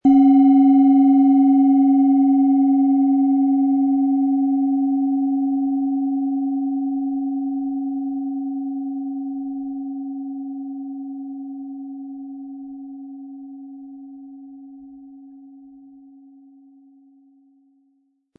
OM Ton
Sie möchten den schönen Klang dieser Schale hören? Spielen Sie bitte den Originalklang im Sound-Player - Jetzt reinhören ab.
SchalenformBihar
HerstellungIn Handarbeit getrieben
MaterialBronze